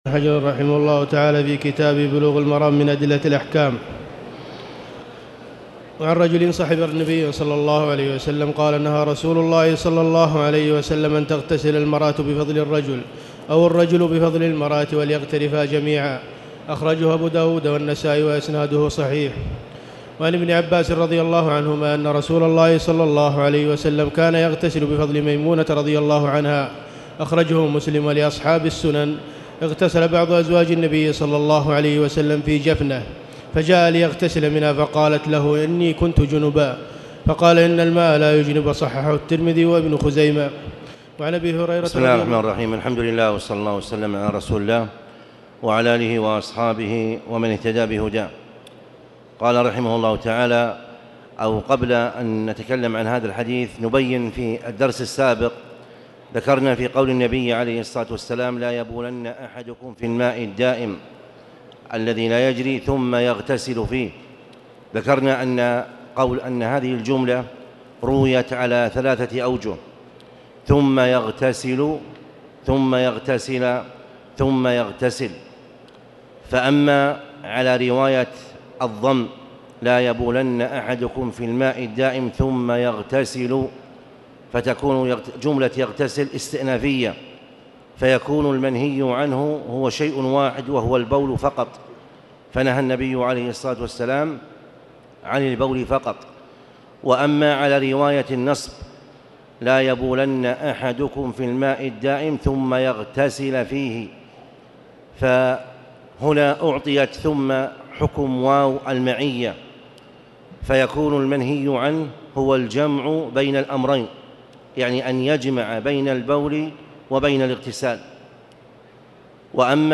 تاريخ النشر ٢٢ ربيع الأول ١٤٣٨ هـ المكان: المسجد الحرام الشيخ